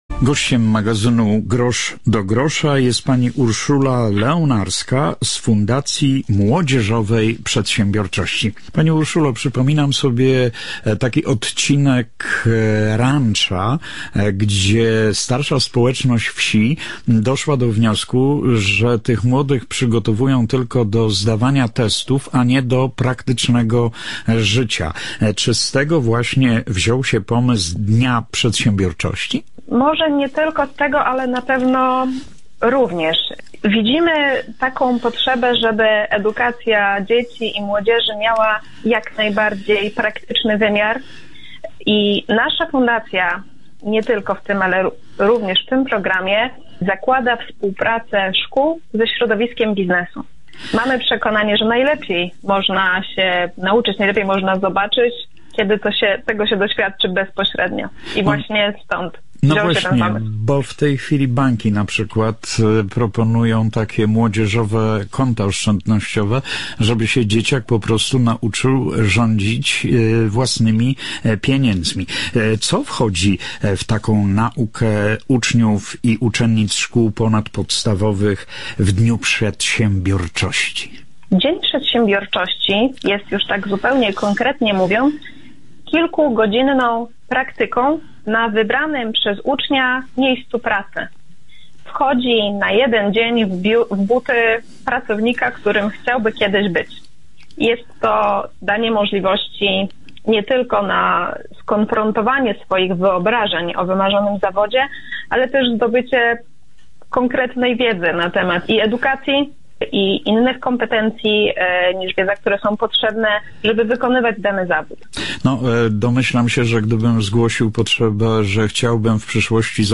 Dzień przedsiębiorczosci na antenie Radia Eska Żary